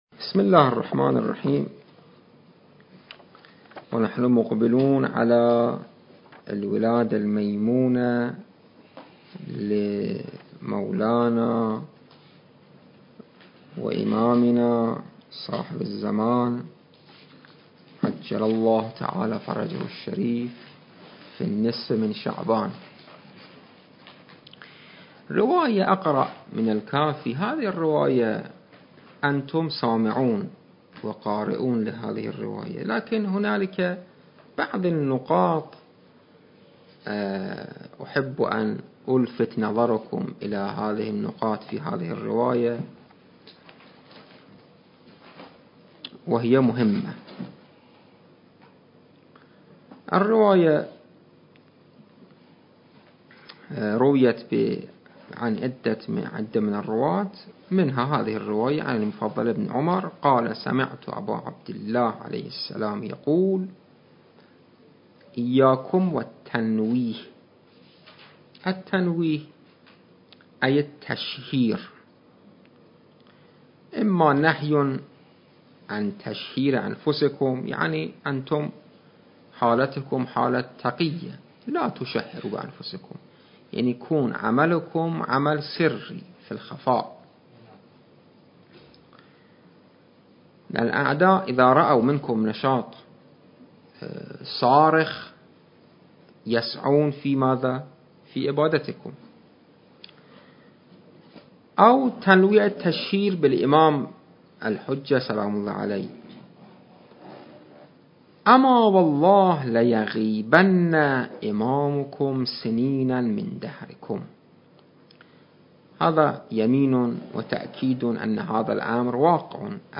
التاريخ: 2021 المكان: معهد المرتضى - النجف الأشرف